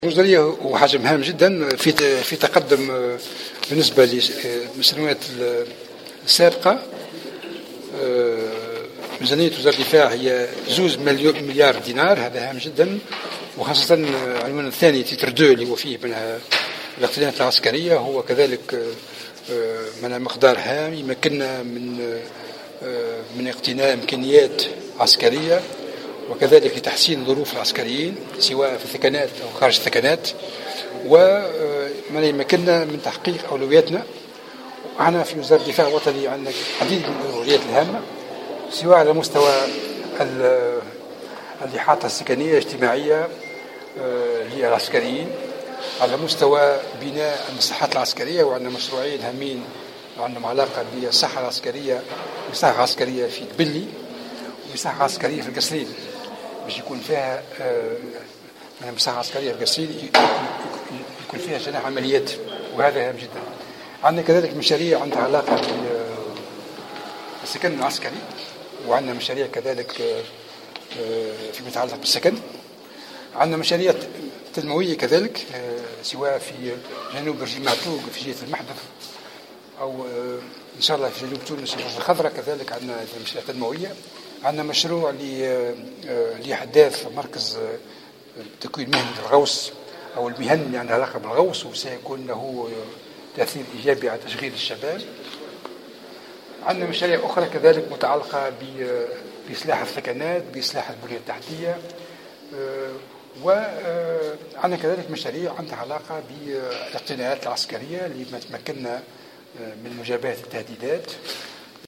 وقال الحرشاني، في تصريح لمراسل الجوهرة أف أم، على هامش جلسة استماع له اليوم الخميس بلجنة الحقوق والحريات بالمبنى الفرعي للبرلمان، إن هذه الميزانية ستخصص في جانب منها لاقتناء عدد هام من المعدات العسكرية، إضافة إلى تحسين ظروف عمل العسكريين في الثكنات وتوفير الإحاطة الإجتماعية والسكنية والصحية بهم، من خلال بناء مصحتين عسكريتين، واحدة في ڨبلي وأخرى في الڨصرين، ستتوفر على جناح للعمليات.